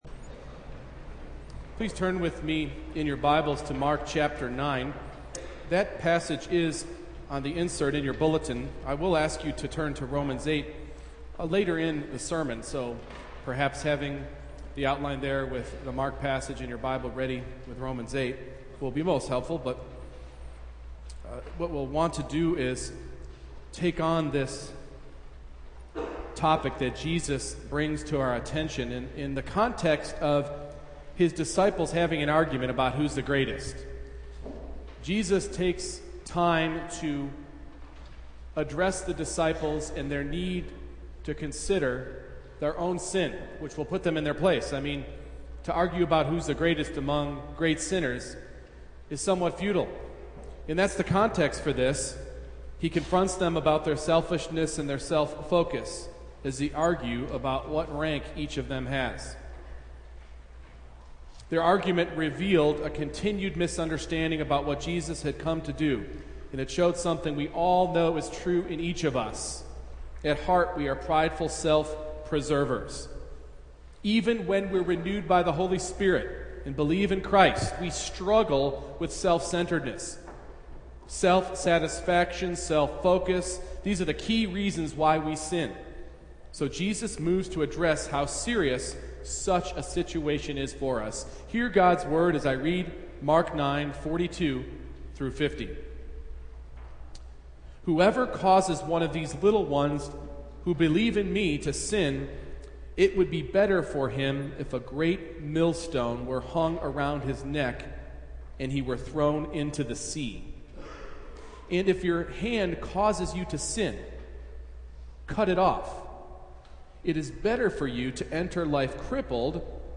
Mark 9:42-50 Service Type: Morning Worship We cannot afford to minimize sin